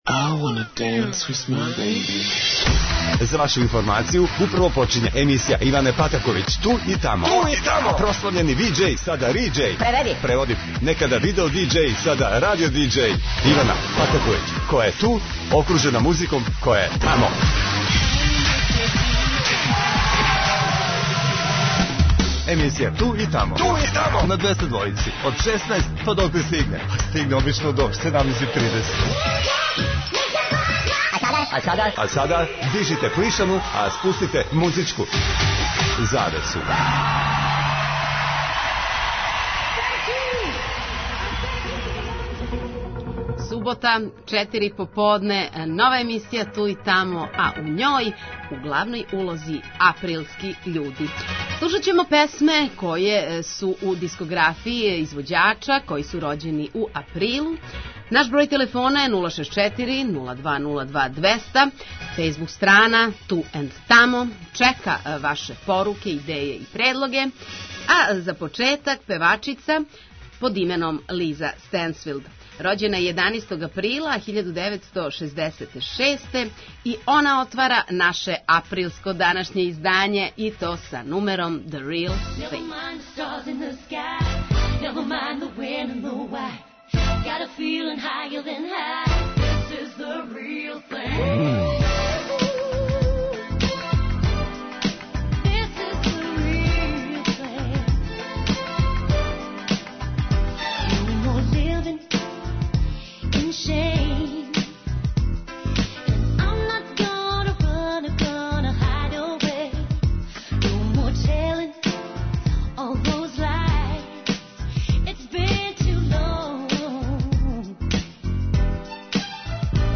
Слушаоци могу у сваком тренутку да се јаве и дају свој предлог на "Ту и тамо" тему...